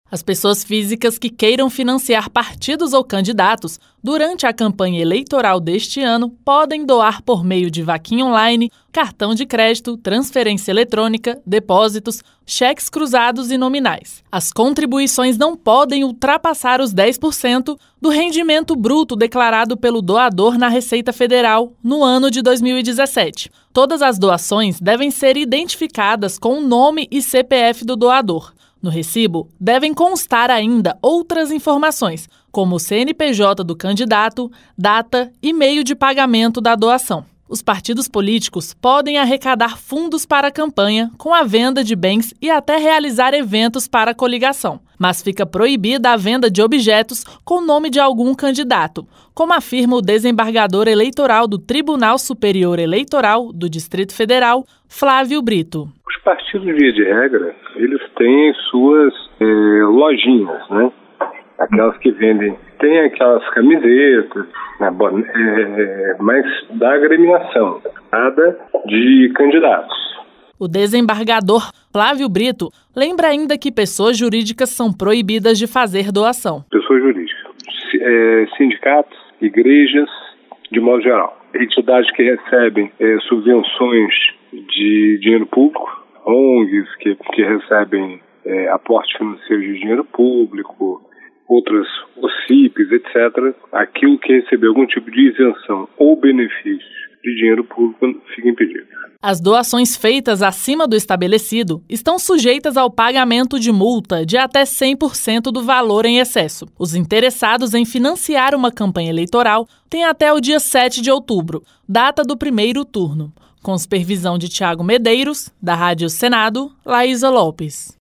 Mais detalhes na reportagem